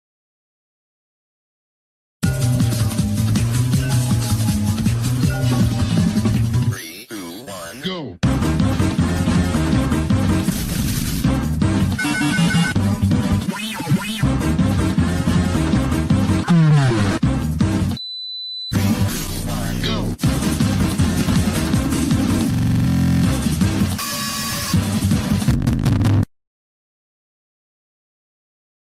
BPM160
Audio QualityPerfect (Low Quality)
CommentsSorry low audio...